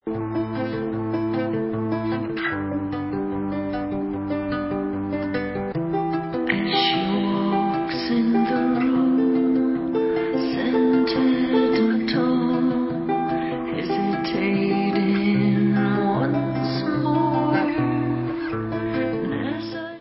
Dance/Trip Hop